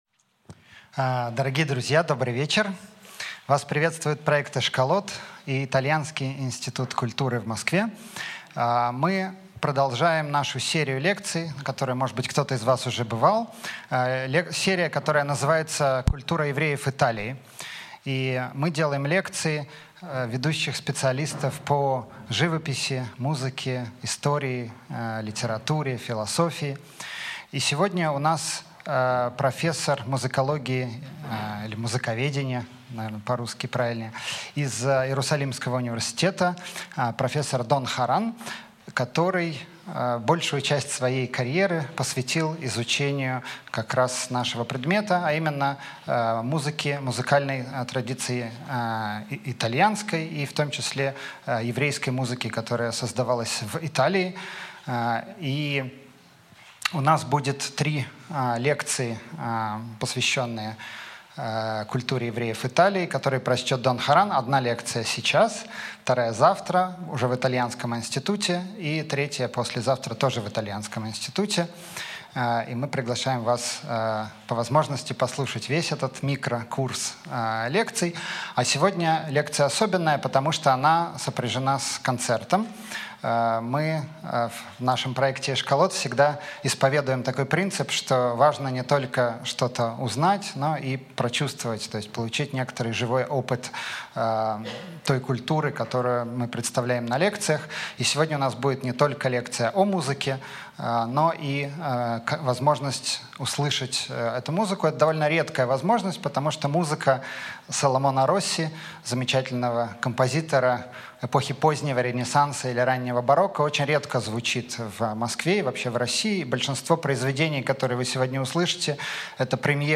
Аудиокнига Саламоне Росси: еврейский композитор при мантуанском дворе | Библиотека аудиокниг